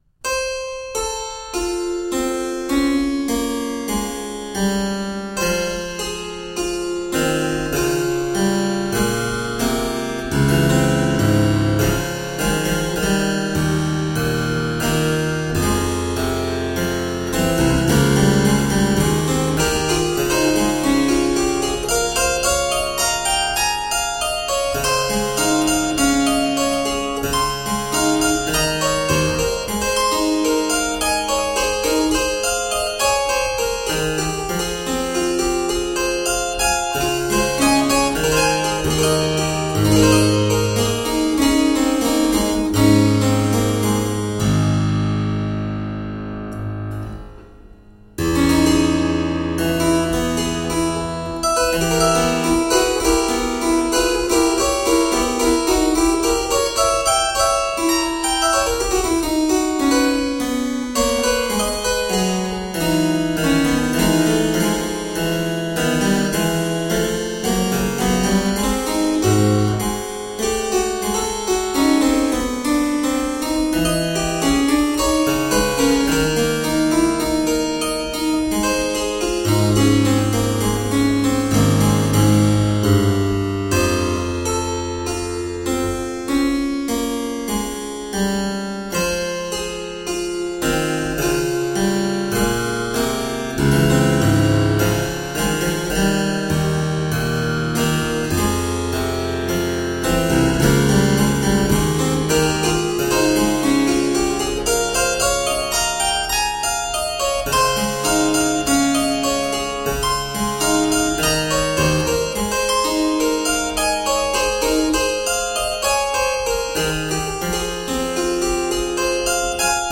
Harpsichord and fortepiano classics.
Rich tones, reflective work.